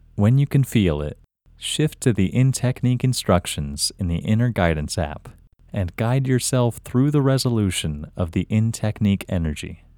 LOCATE Short IN English Male 13